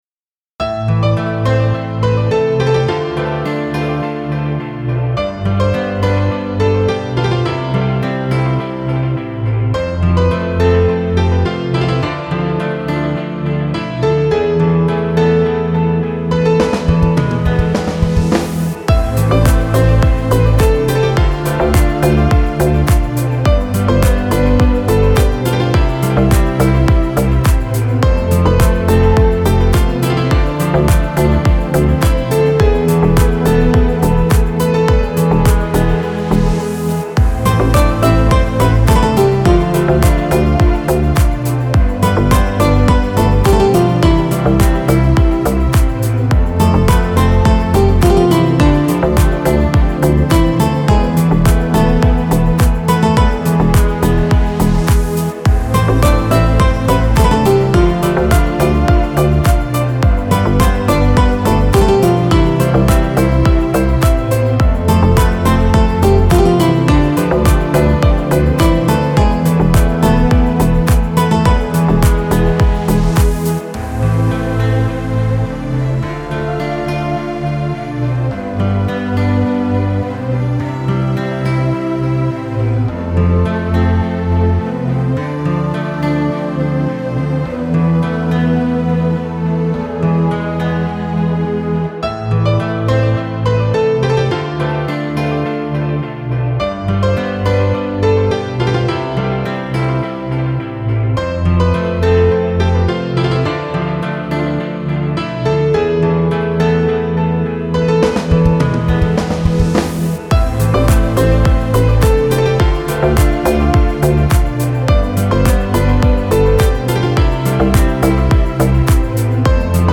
موسیقی بی کلام دیپ هاوس موسیقی بی کلام ریتمیک آرام